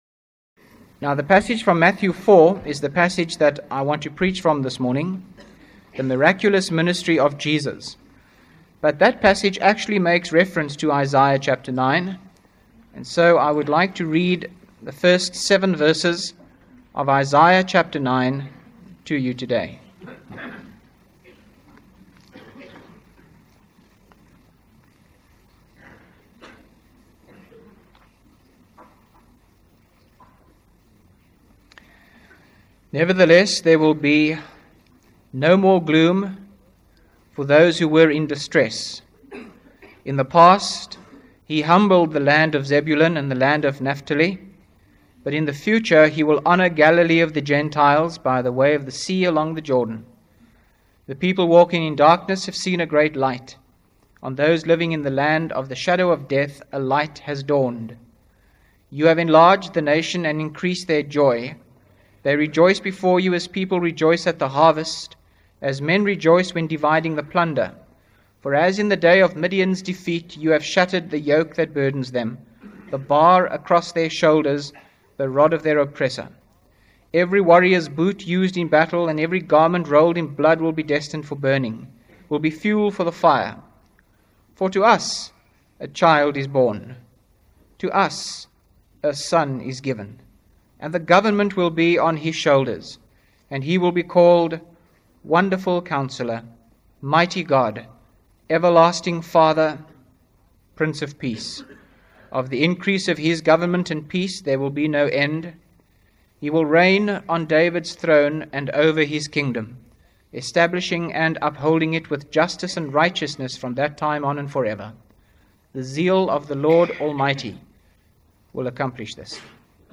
Sermons (St James)